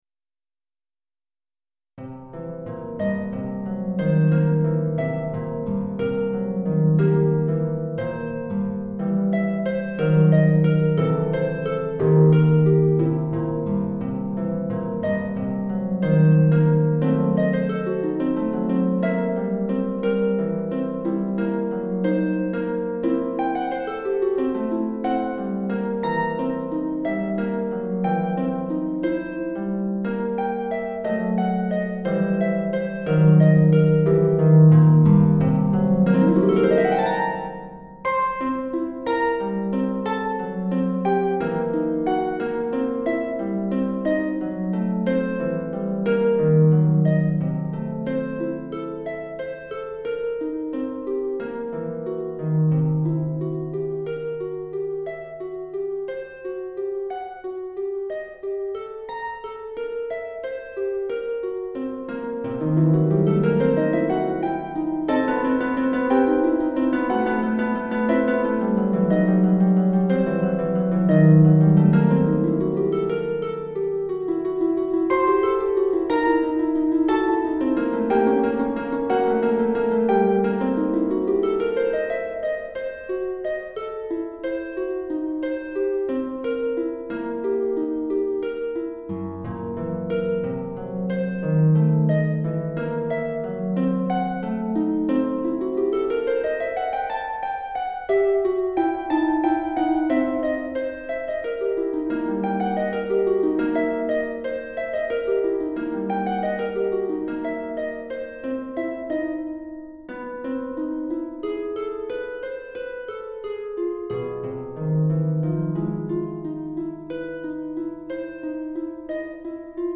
Harpe Solo